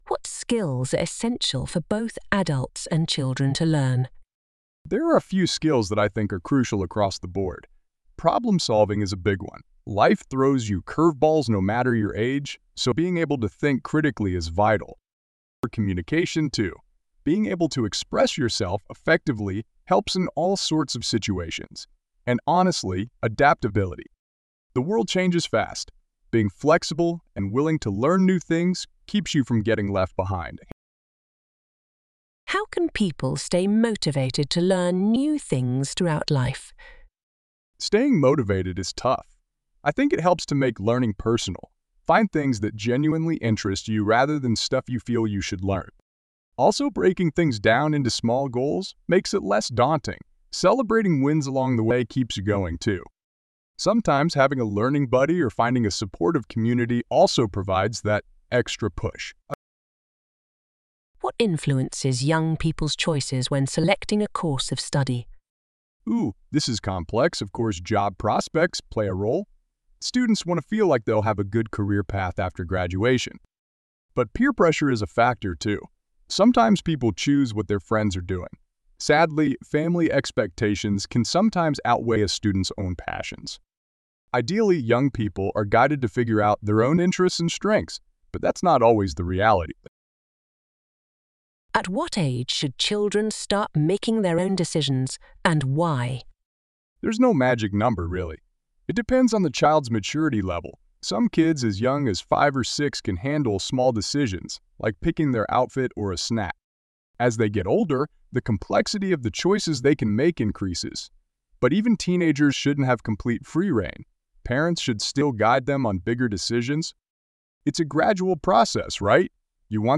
Trong bài viết này, Mc IELTS chia sẻ câu trả lời mẫu band 8.0+ từ cựu giám khảo IELTS, kèm theo các câu hỏi mở rộng và bản audio từ giáo viên bản xứ để bạn luyện phát âm, ngữ điệu và tốc độ nói tự nhiên.